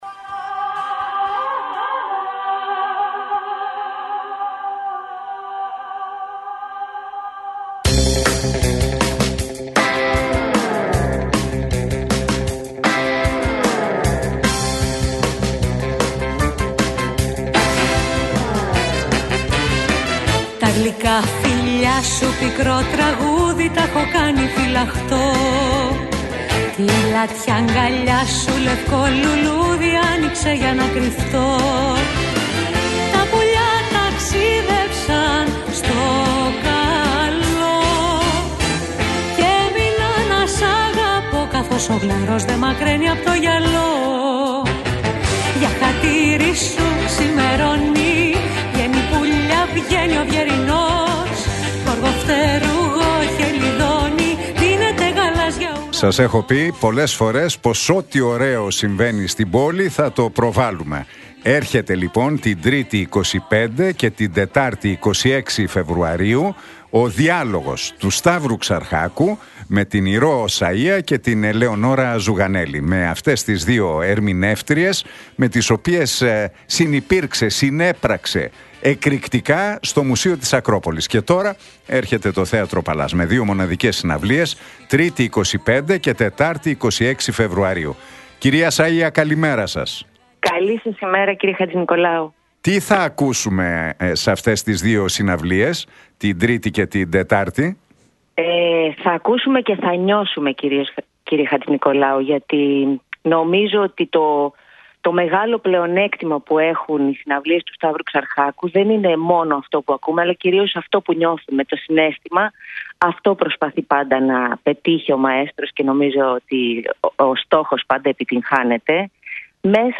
Για τις δύο συναυλίες του Σταύρου Ξαρχάκου στο Θέατρο Παλλάς μίλησε η Ηρώ Σαΐα στον Νίκο Χατζηνικολάου από την συχνότητα του Realfm 97,8.